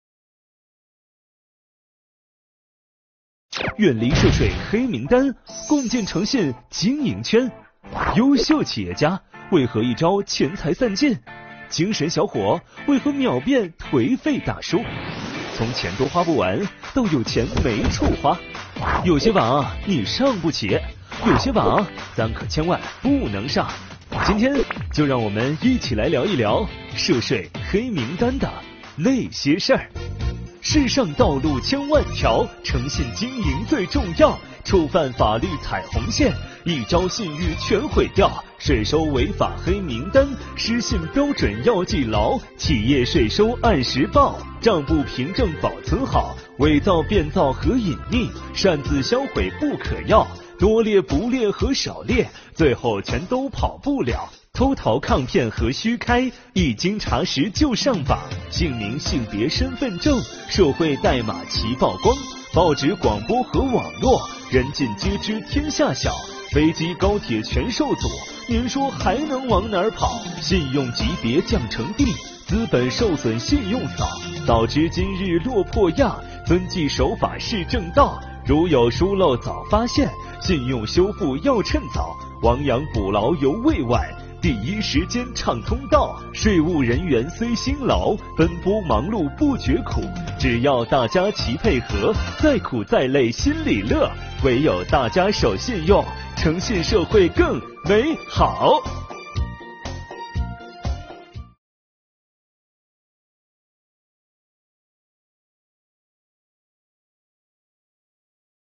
动漫以通俗易懂、朗朗上口的语言以及富有天津地方特色的快板节奏，介绍了涉税“黑名单”，引导纳税人缴费人依法诚信纳税，共同维护税收秩序。
该作品节奏明快，动画角色鲜活，秒趣横生，深入浅出地宣传了企业家要依法诚信纳税，做好模范带头表率作用。